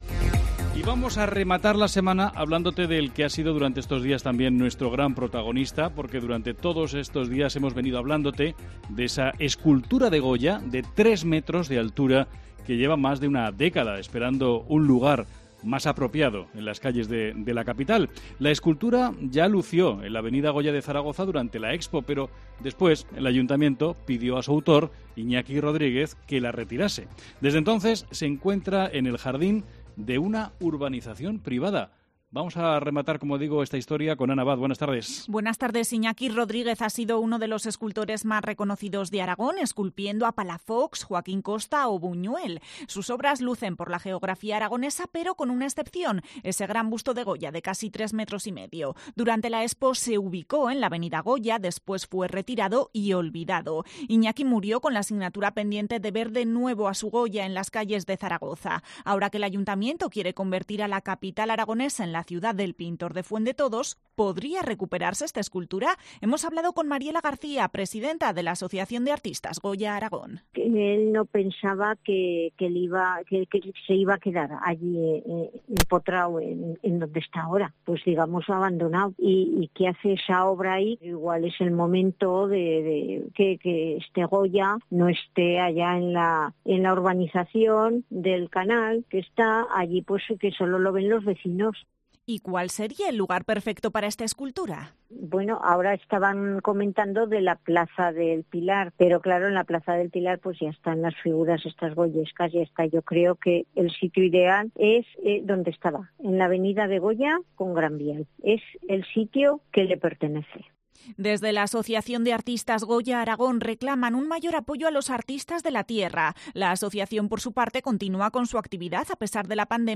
Hablamos con la Asociación de Artistas Plásticos Goya Aragón sobre el busto del pintor de Fuendetodos que se encuentra abandonado en una urbanización desde hace 12 años